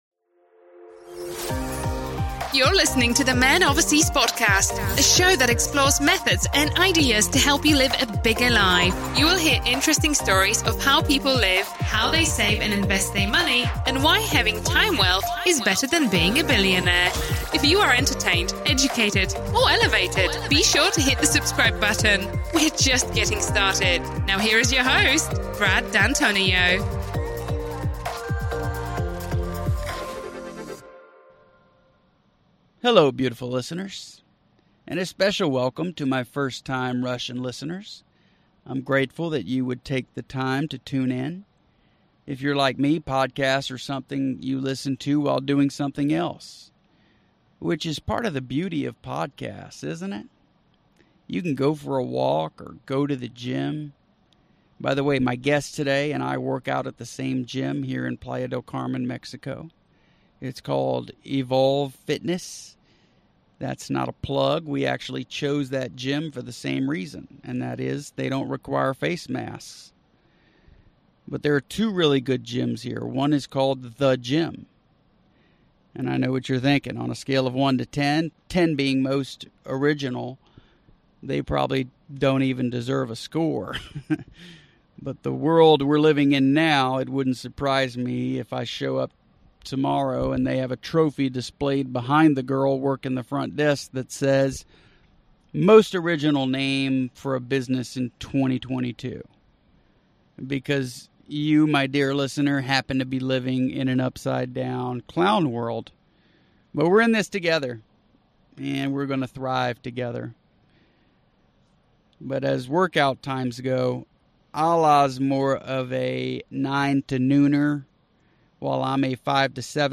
For this episode, I reserved a private room in a co-working space.